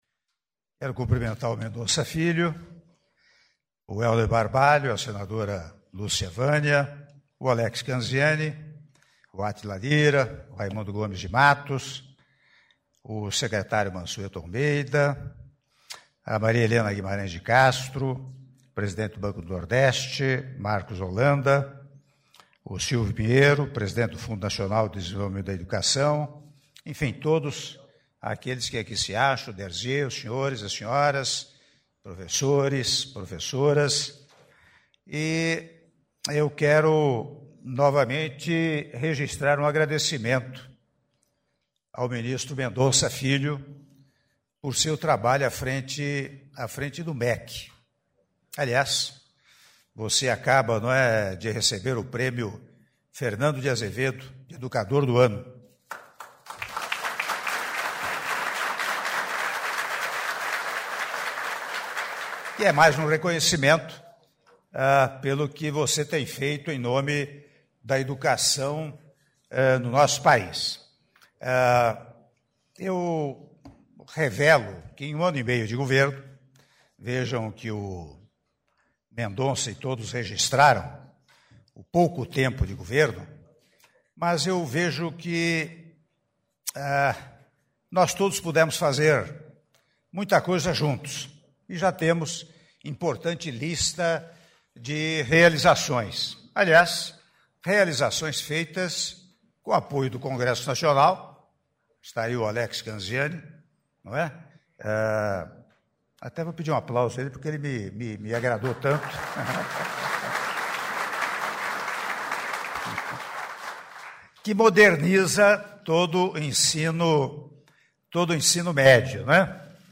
Áudio do discurso do Presidente da República, Michel Temer, durante cerimônia de sanção da Lei do Novo FIES - Palácio do Planalto (07min24s)